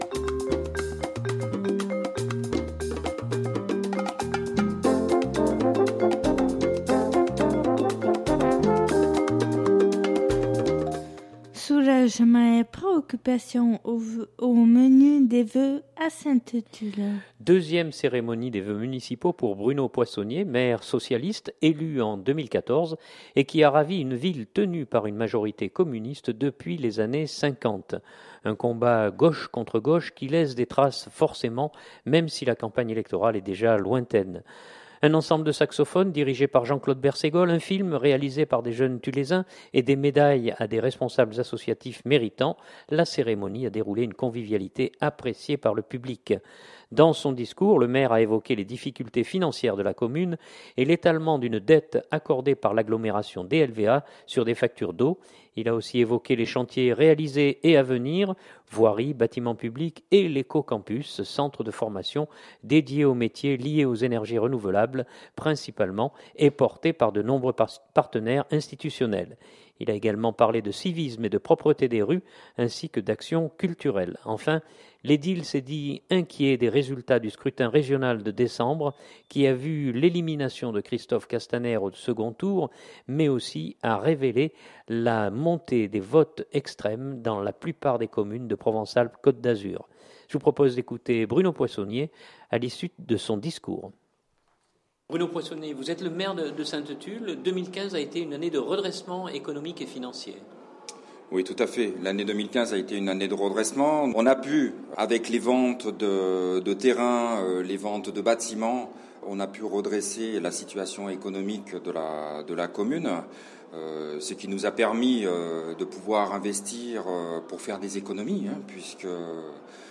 Deuxième cérémonie des vœux municipaux pour Bruno Poissonnier, maire socialiste élu en 2014 et qui a ravi une ville tenue par une majorité communiste depuis les années cinquante.
Je vous propose d’écouter Bruno Poissonnier à l’issue de son discours.